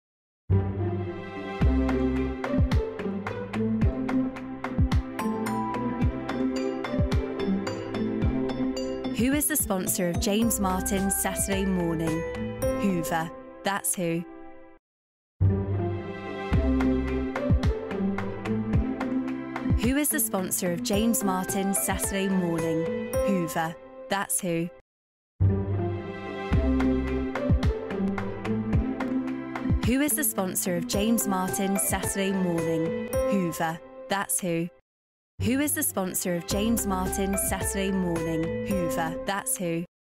Programas de televisão
Sennheiser MK4
Jovem adulto
Mezzo-soprano